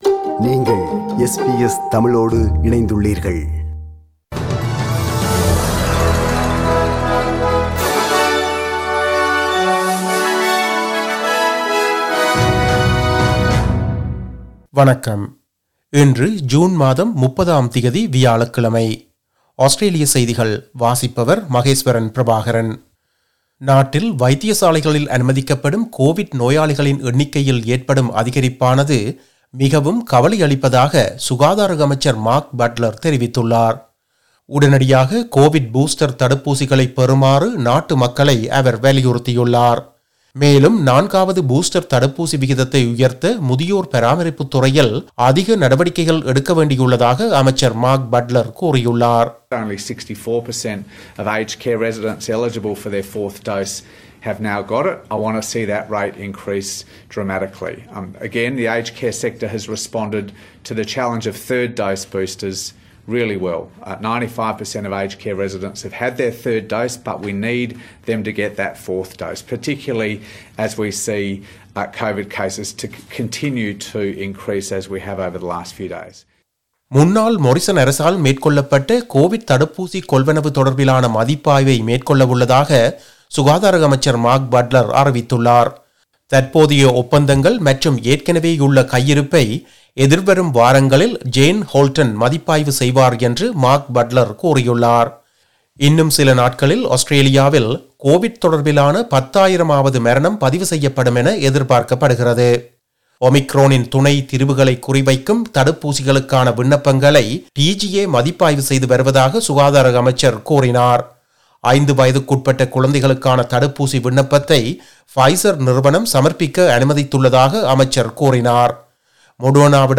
Australian news bulletin for Thursday 30 June 2022.